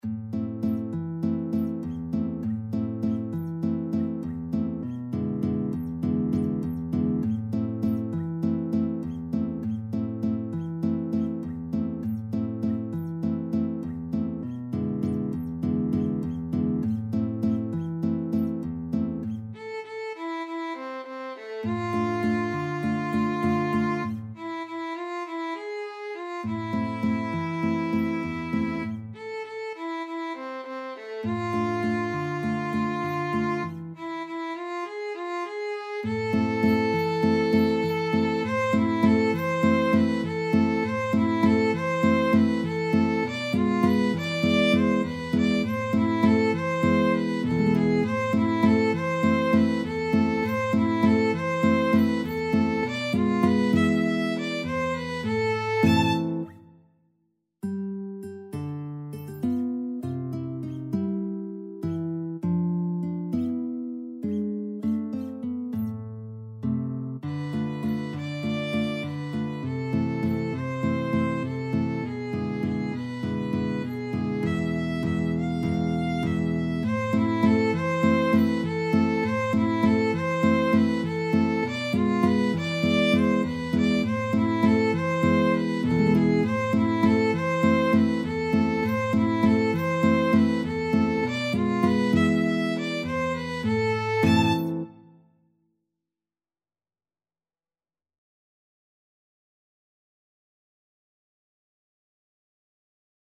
A sultry and atmospheric piece.
Moderato
World (View more World Violin-Guitar Duet Music)